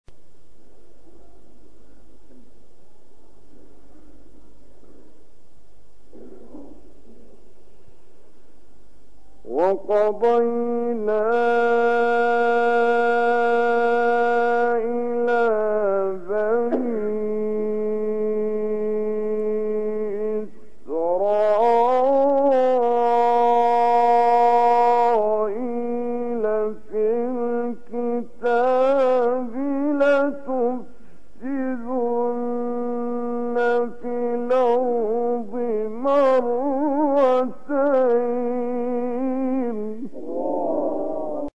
15 فراز از «کامل یوسف» در مقام بیات
گروه شبکه اجتماعی: فرازهای صوتی از کامل یوسف البهتیمی که در مقام بیات اجرا شده است، می‌شنوید.